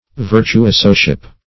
Search Result for " virtuosoship" : The Collaborative International Dictionary of English v.0.48: Virtuosoship \Vir`tu*o"so*ship\, n. The condition, pursuits, or occupation of a virtuoso.
virtuosoship.mp3